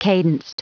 Prononciation du mot cadenced en anglais (fichier audio)
Prononciation du mot : cadenced